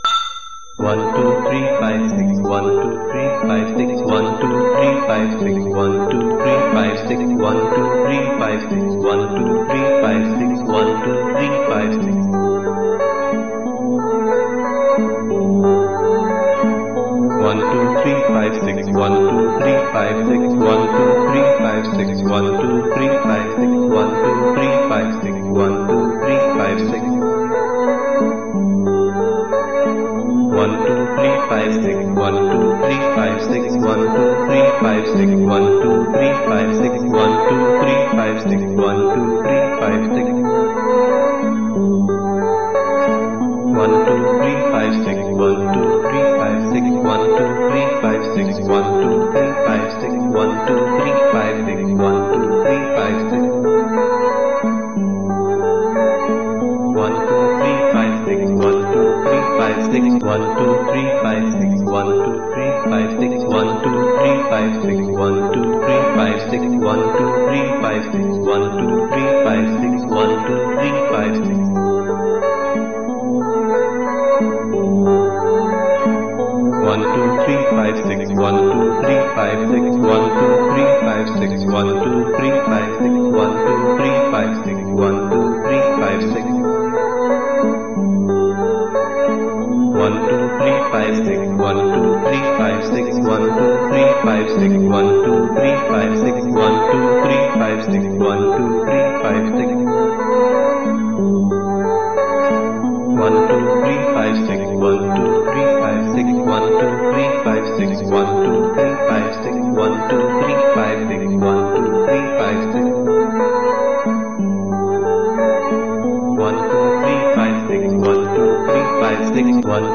5-Mins-36-Cycles-With-Voice.mp3